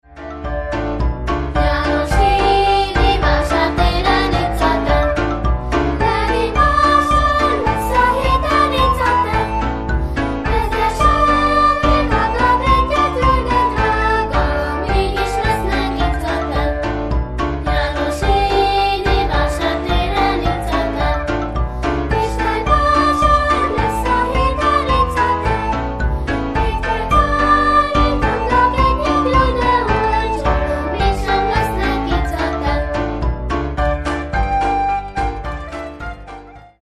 kisiskolások adják elő.